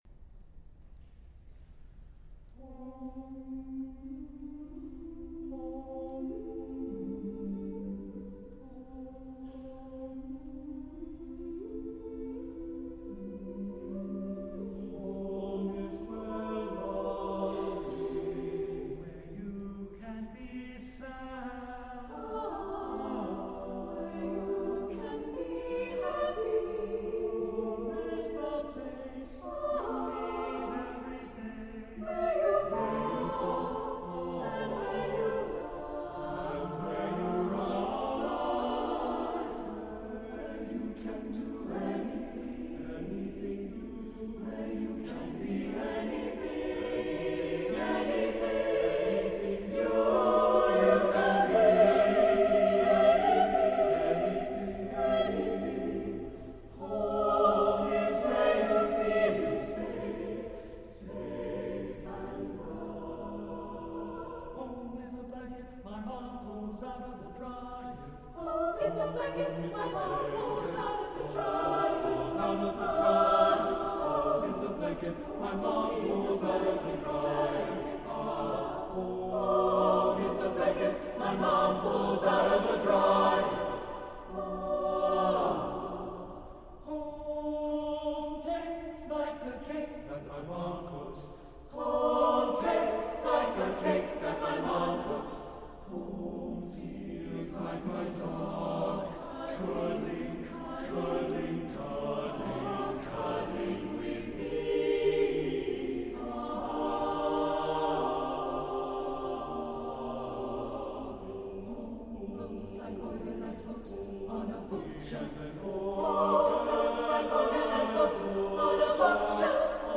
setting is both contemporary and timeless.
SATB divisi